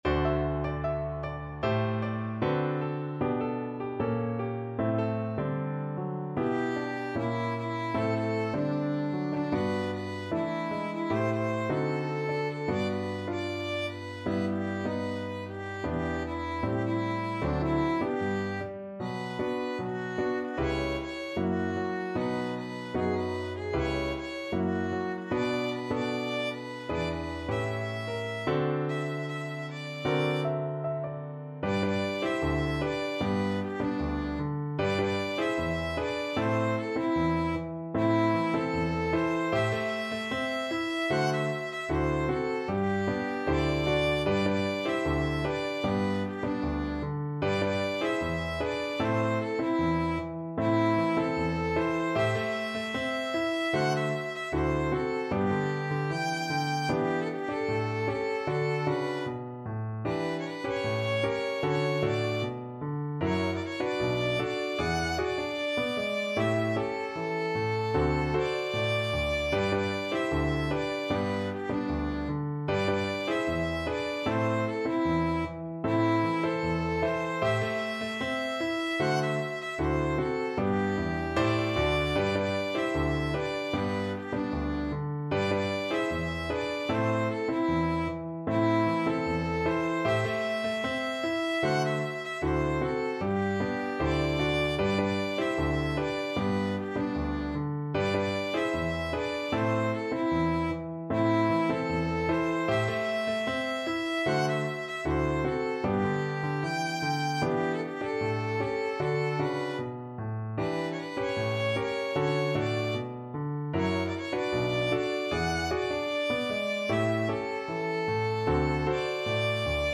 2/2 (View more 2/2 Music)
Pop (View more Pop Violin Music)